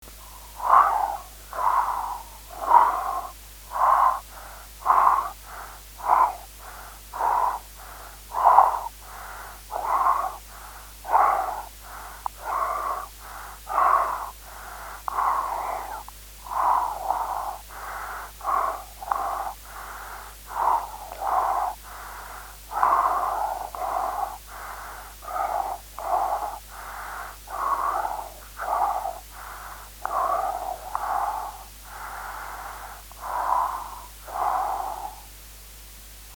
Â The constant air flow in and out because of breathing is modulated by your vocal cords in silent speaking when you think and it is the recording of this modulation that creates ‘speak thinking’.